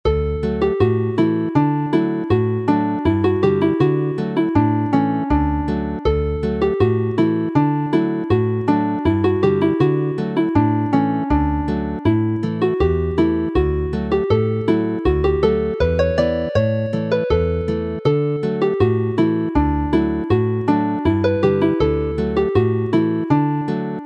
Chwarae'r alaw yn D
Play the melody in D